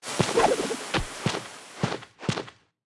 Media:Sfx_Anim_Baby_Dynamike.wavMedia:Sfx_Anim_Classic_Dynamike.wavMedia:Sfx_Anim_Super_Dynamike.wavMedia:Sfx_Anim_Ultra_Dynamike.wavMedia:Sfx_Anim_Ultimate_Dynamike.wav 动作音效 anim 在广场点击初级、经典、高手、顶尖和终极形态或者查看其技能时触发动作的音效
Sfx_Anim_Baby_Dynamike.wav